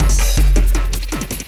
53 LOOP 03-R.wav